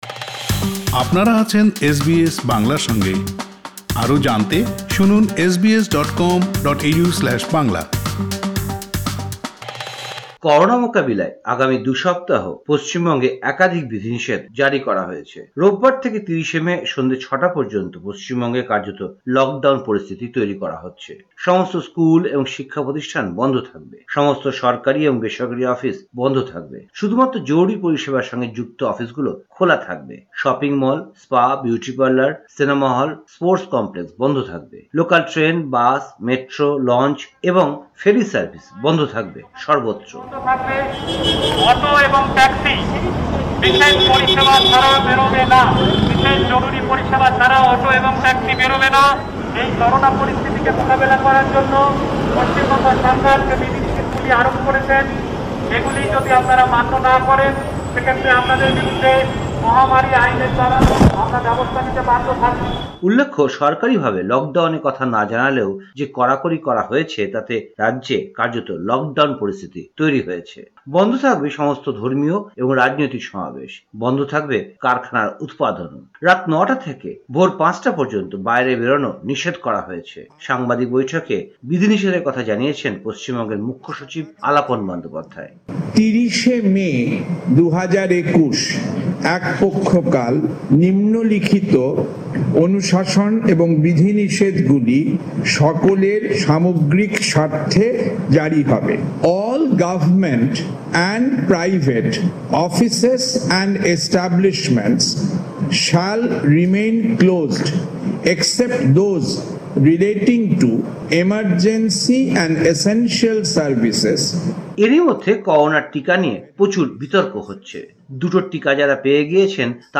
ভারতীয় সংবাদ: ১৭ মে ২০২১
কলকাতা থেকে